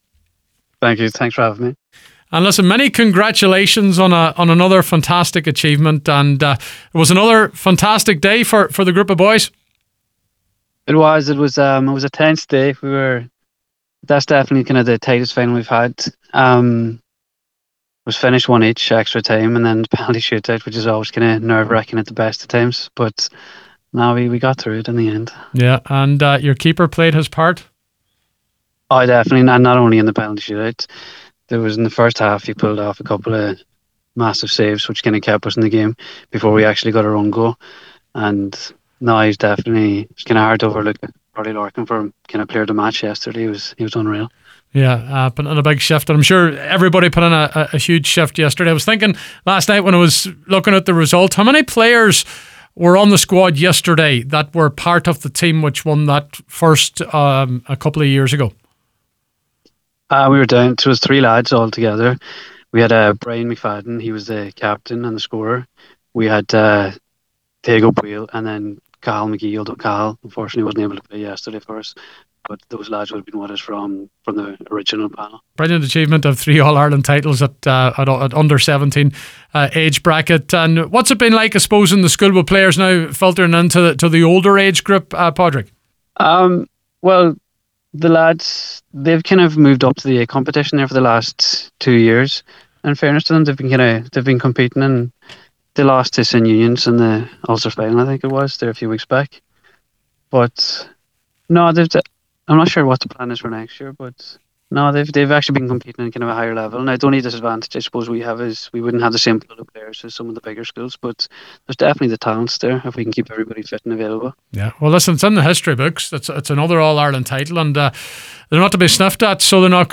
on The Score programme on Thursday evening to reflect on the remarkable achievement…